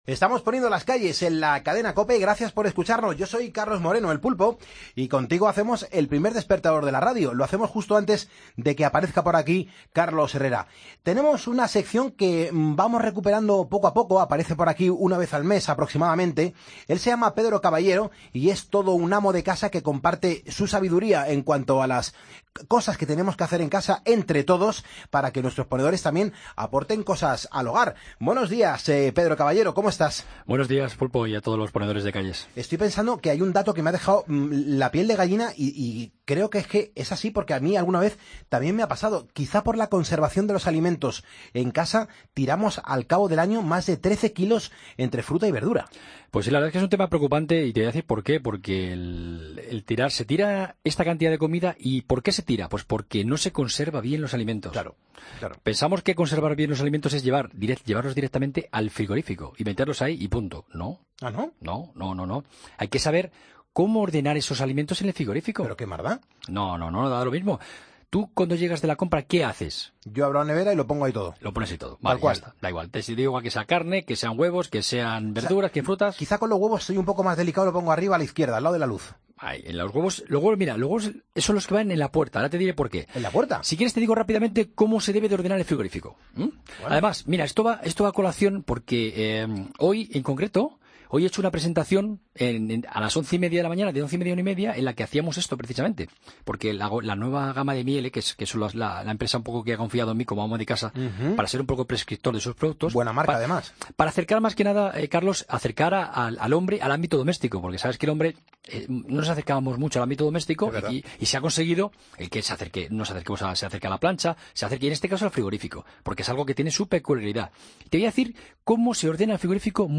Entrevistas en Poniendo las calles